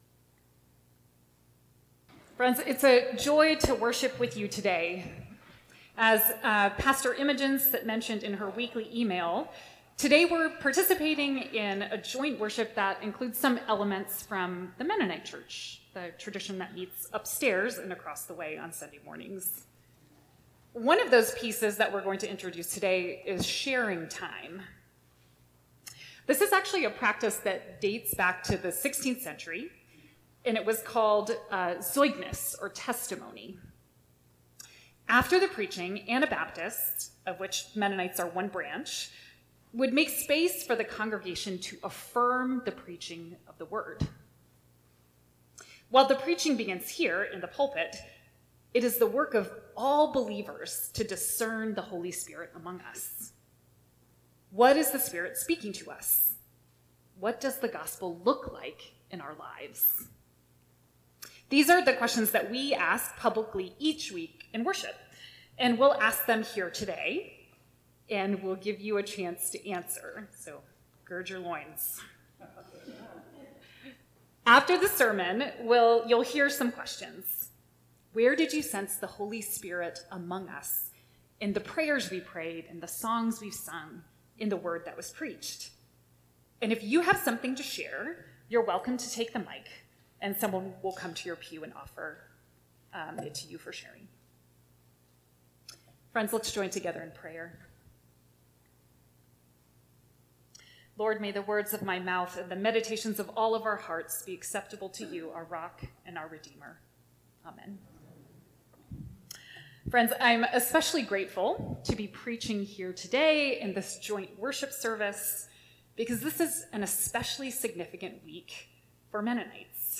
Scripture: John 2:1-11 We held a joint service with Church of the Good Shepherd Episcopal this Sunday, the congregation we rent space from.